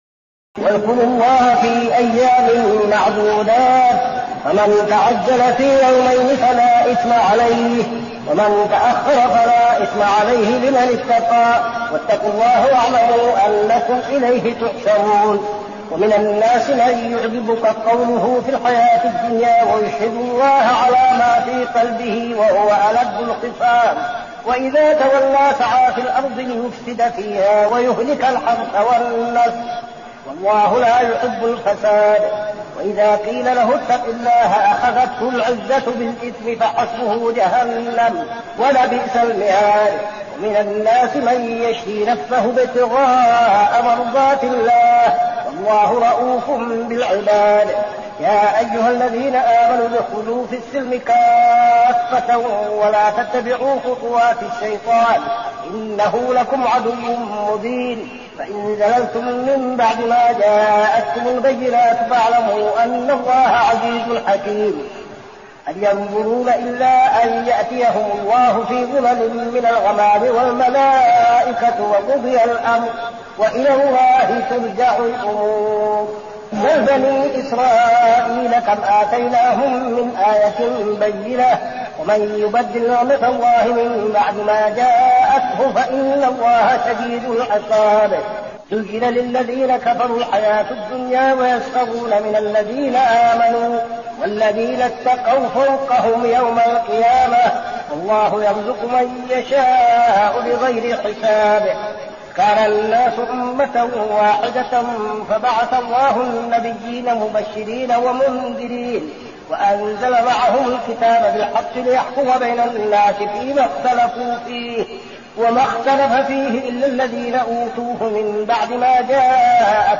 صلاة التراويح ليلة 2-9-1402هـ سورة البقرة 203-252 | Tarawih prayer Surah Al-Baqarah > تراويح الحرم النبوي عام 1402 🕌 > التراويح - تلاوات الحرمين